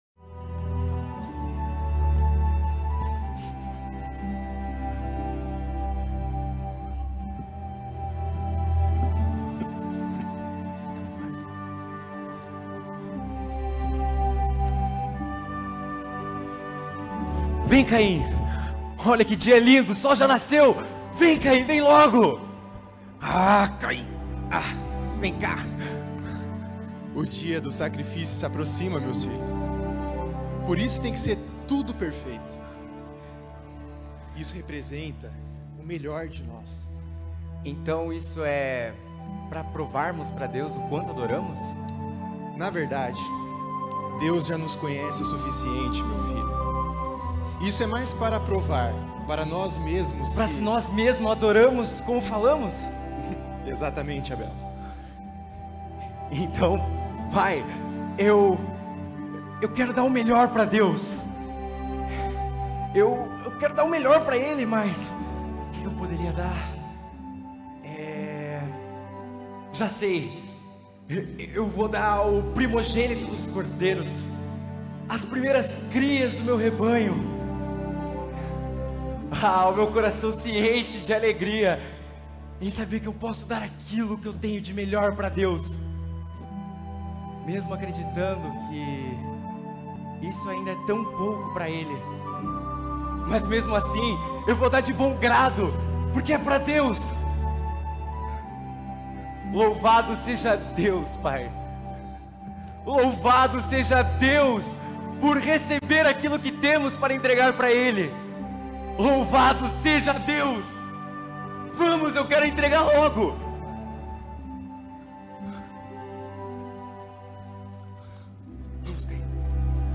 Mensagem apresentada
na Igreja Batista do Bacacheri